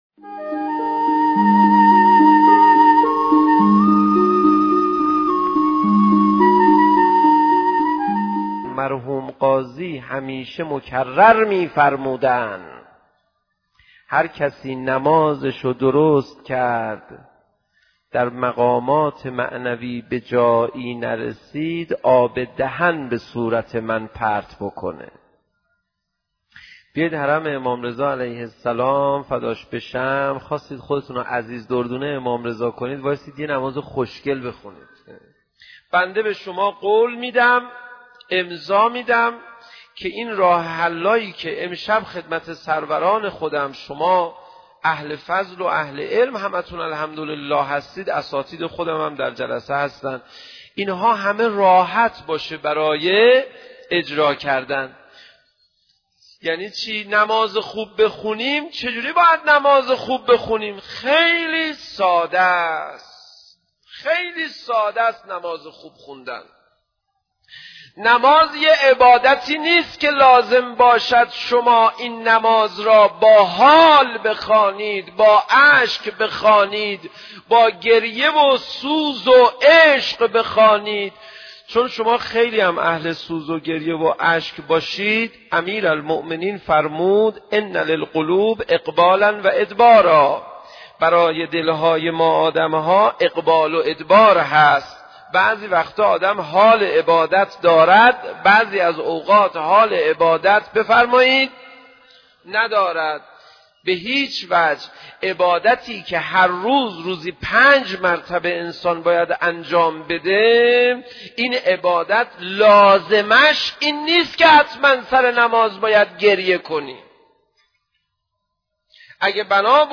سخنرانی حجت الاسلام پناهیان در مورد نماز